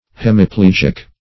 Hem`i"pleg"ic, a.